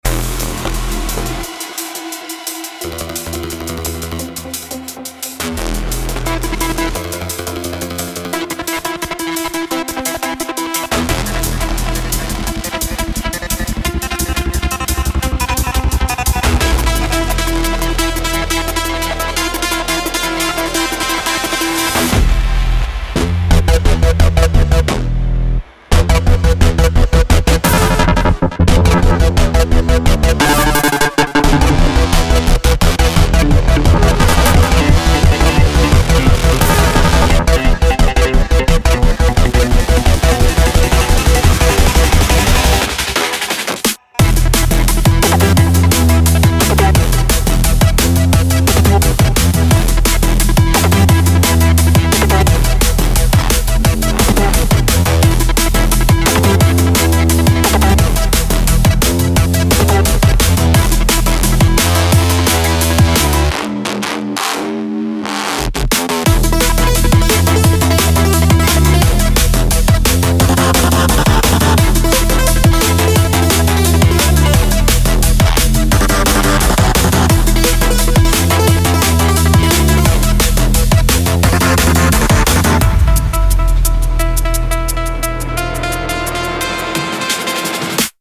• 70 Arpeggiators — fast, rhythmic arps that range from shimmering patterns to driving pulses
• EDM power — energetic grooves, syncopated motion, rich harmonic content
• Cinematic expression — evolving textures, tension & release, expressive modulations
Expect everything from shimmering, tremolo style arps to tight plucky pulses, from emotive sequence beds to evolving rhythmic motifs.
• * The video and audio demos contain presets played from Pulse Protocol sound bank, every single sound is created from scratch with Spire.
• * All sounds of video and audio demos are from Pulse Protocol (except drums and additional arrangements).